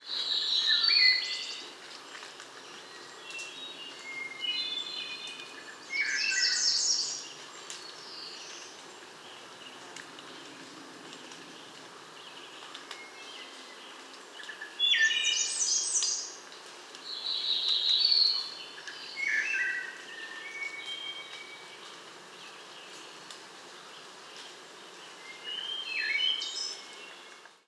Wood Thrush – Hylocichla mustelina
Song Mont-Orford National Park, QC.